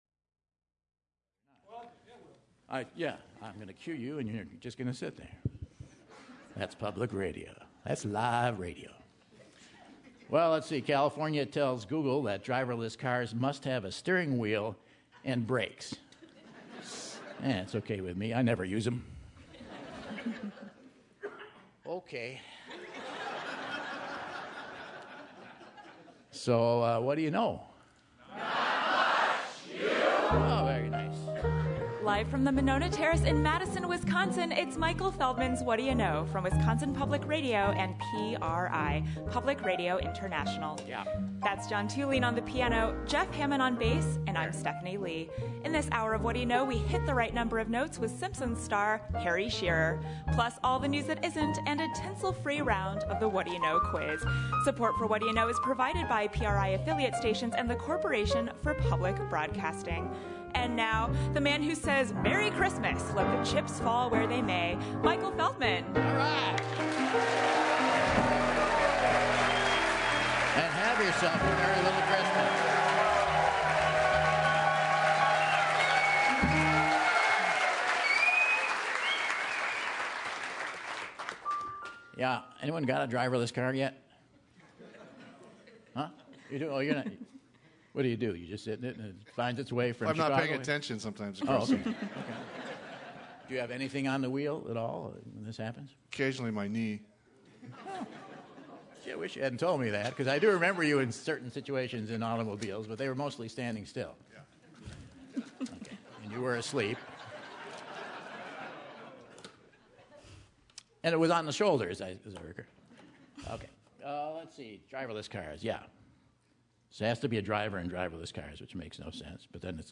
December 19, 2015 - Madison, WI - Monona Terrace - HOLIDAY PARTY | Whad'ya Know?